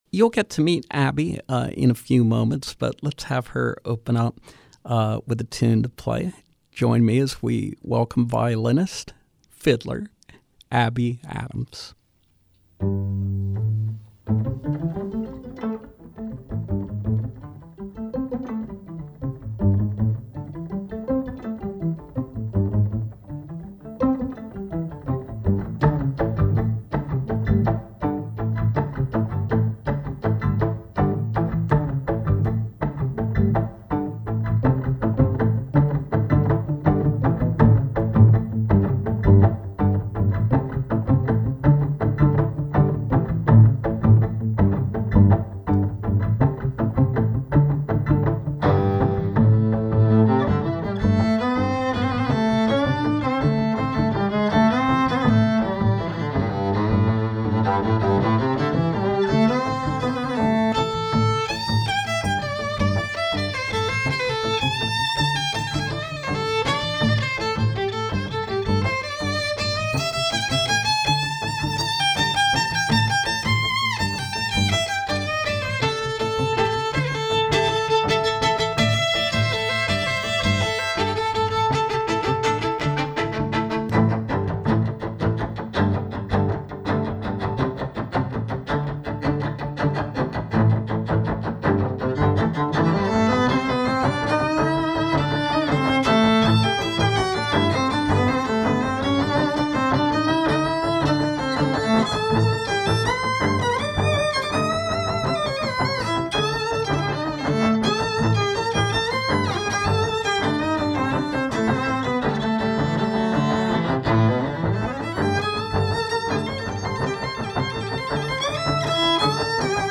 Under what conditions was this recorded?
Live performance with violinist